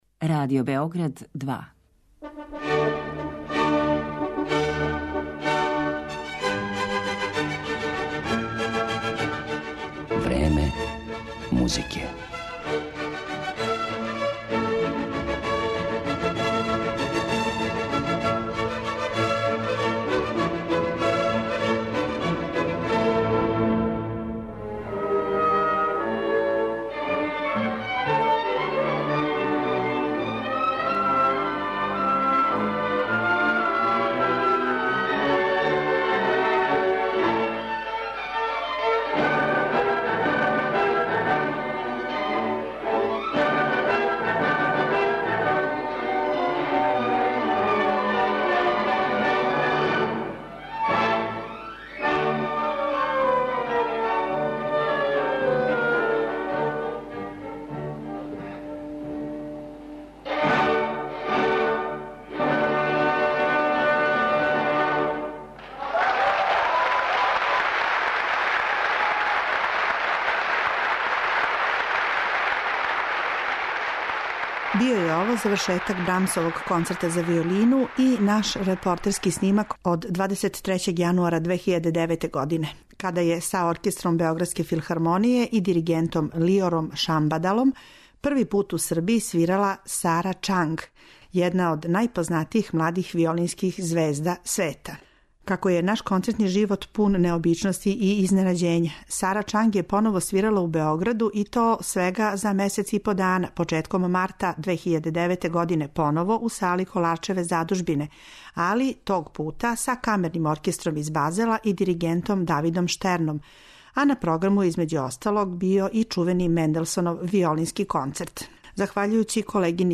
Први пут је гостовала у Београду 2009. године и тада смo снимили интервју с њом, а осим што говори о свом животу, схватању уметности и њене мисије у свету, Сара Ћанг ће вам свирати композиције Глука, Паганинија, Сибелијуса и Вивалдија.